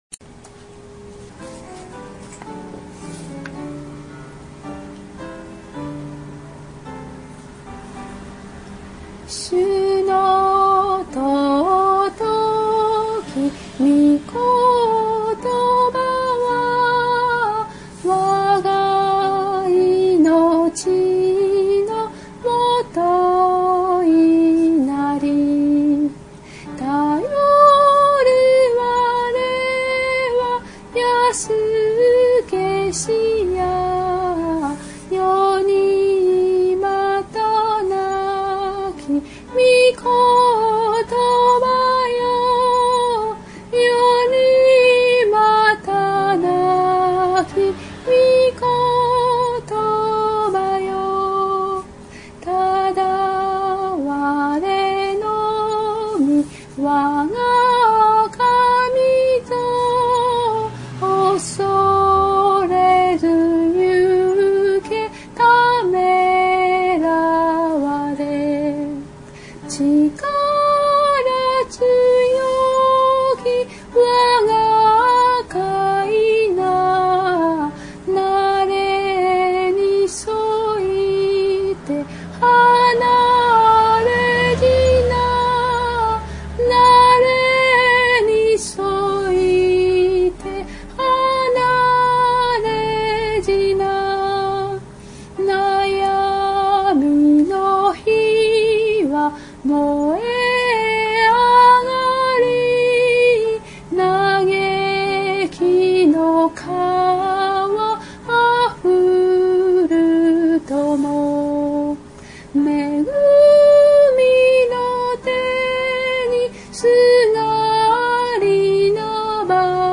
主の尊きみことばは 讃美歌 ２８４
（徳島聖書キリスト集会集会員）